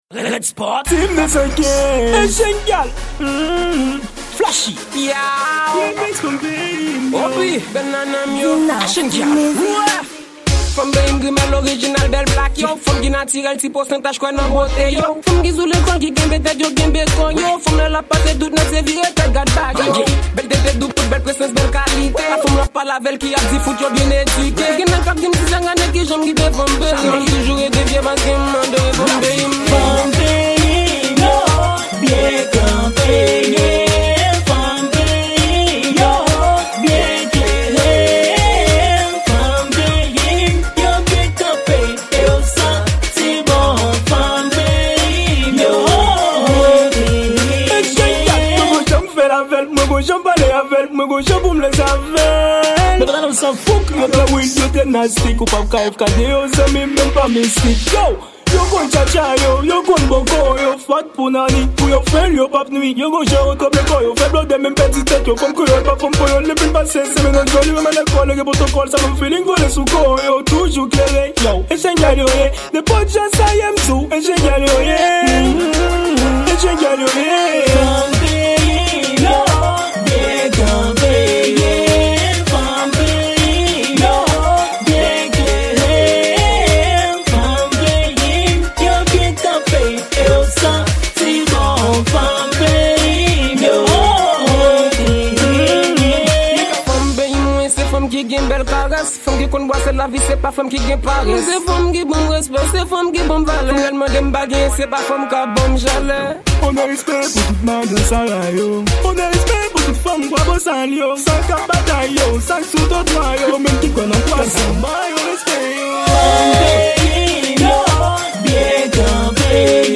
Remix
Genre: WORLD